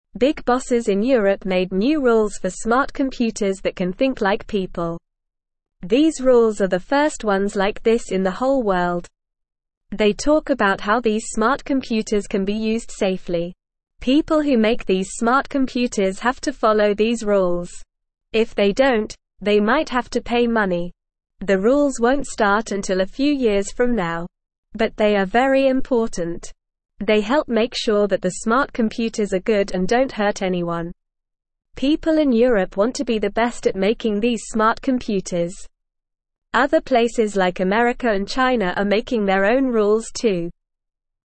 Normal
English-Newsroom-Beginner-NORMAL-Reading-New-Rules-for-Smart-Computers-to-Keep-People-Safe.mp3